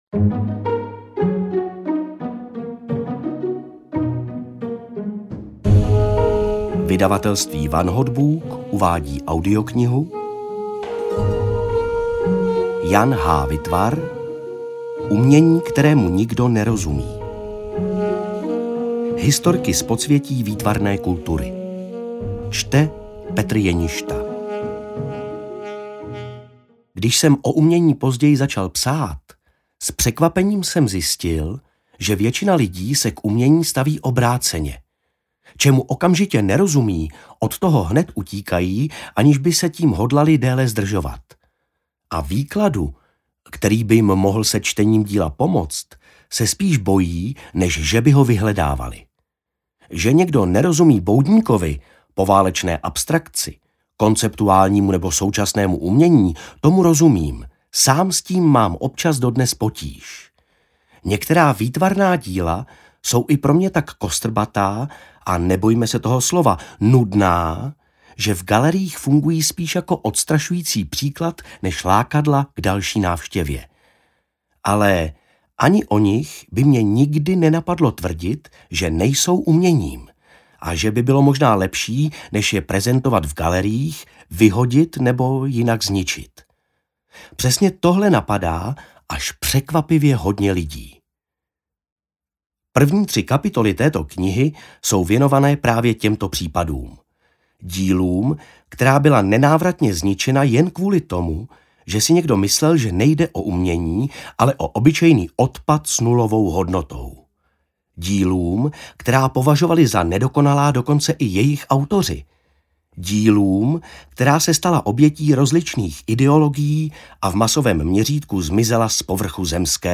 Umění, kterému nikdo nerozumí: Historky z podsvětí výtvarné kultury audiokniha
Ukázka z knihy